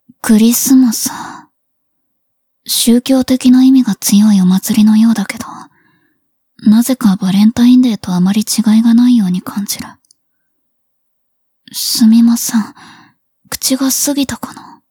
文件 文件历史 文件用途 全域文件用途 圣诞节送礼.wav （WAV音频文件，总共长15秒，码率1.54 Mbps，文件大小：2.73 MB） 摘要 灵魂潮汐：克拉丽丝语音 许可协议 本作品仅以介绍为目的在此百科中以非盈利性方式使用，其著作权由原著作权人保留。